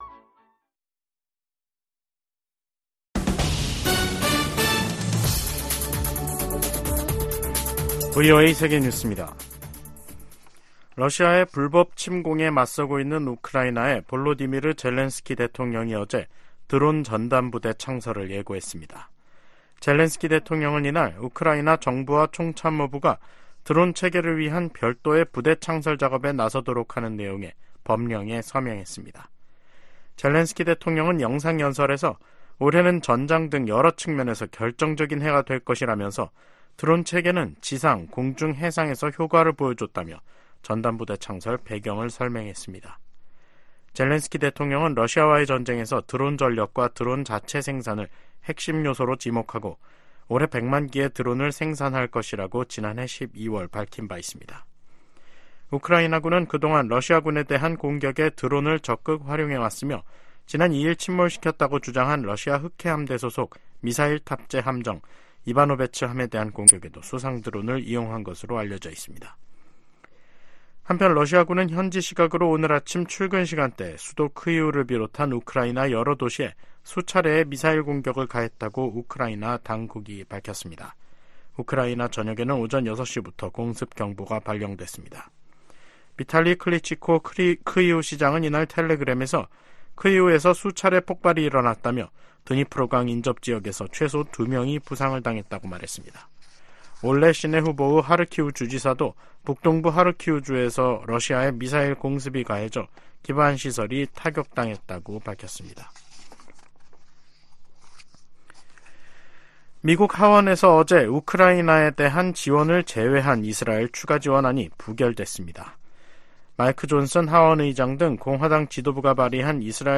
VOA 한국어 간판 뉴스 프로그램 '뉴스 투데이', 2024년 2월 7일 2부 방송입니다. 미국이 유엔 안보리에서 러시아의 북한제 무기 사용을 강하게 비난하고, 양국에 책임 묻기를 촉구했습니다. 한국 신임 외교부 장관과 중국 외교부장이 첫 통화를 하고 현안을 논의했습니다.